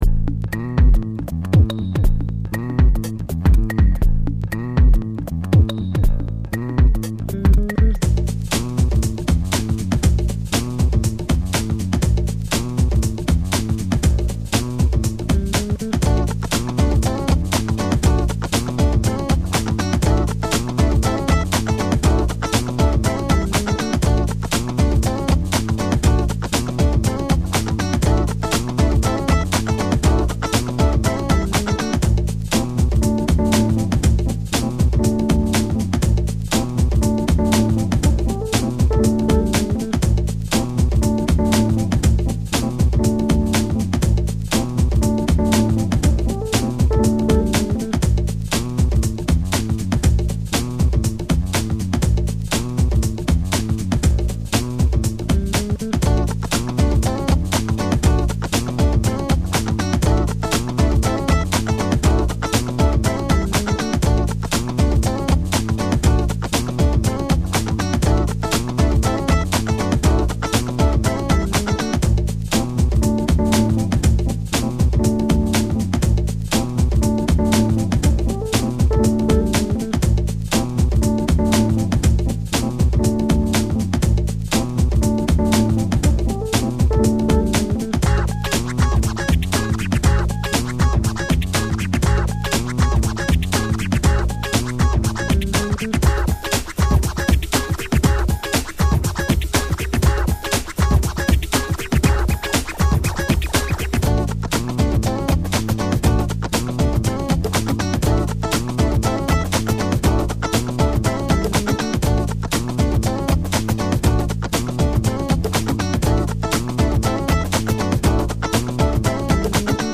(House)